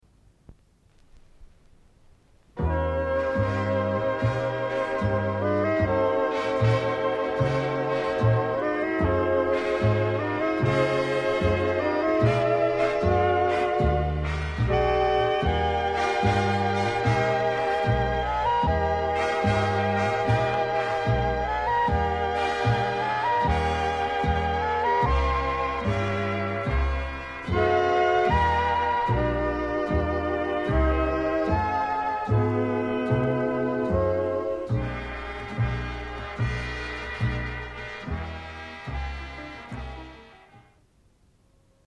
le style "swing"